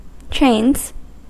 Ääntäminen
Ääntäminen US : IPA : [tʃɹeɪnz] Tuntematon aksentti: IPA : /ˈtɹeɪnz/ Haettu sana löytyi näillä lähdekielillä: englanti Käännöksiä ei löytynyt valitulle kohdekielelle.